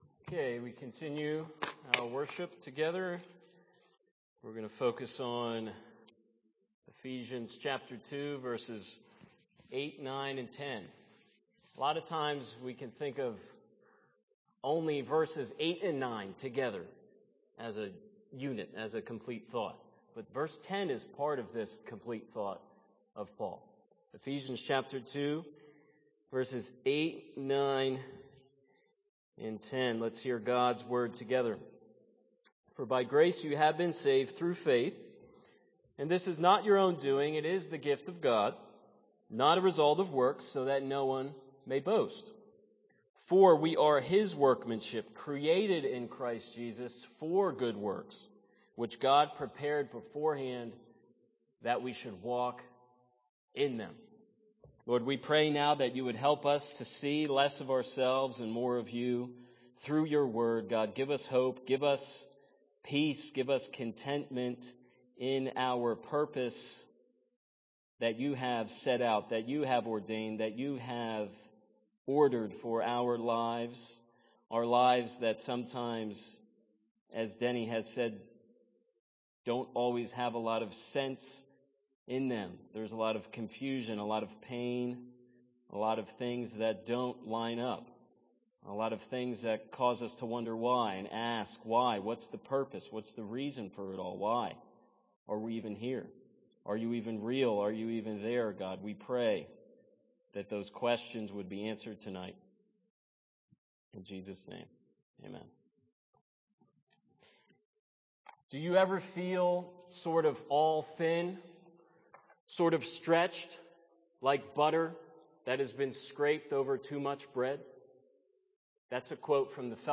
00:00 Download Copy link Sermon Text Ephesians 2:8–10